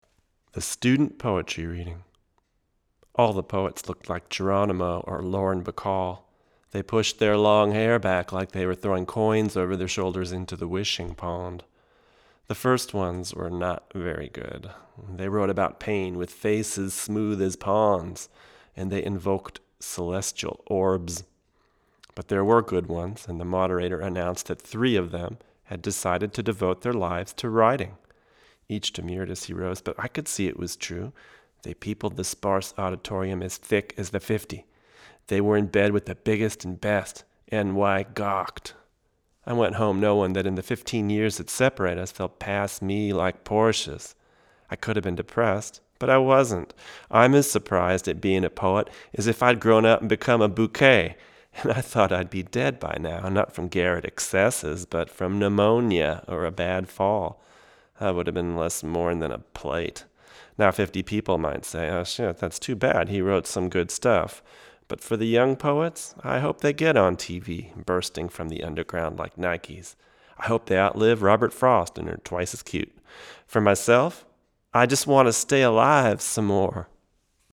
Here are 33 quick, 1-take MP3s using this UM70 and M70 in a large room going into a Audient Black mic pre, into a Sony PCM D1 flash recorder, with MP3s made from Logic. These tracks are just straight signal with no additional EQ, compresson or effects:
VOICE OVER (M70):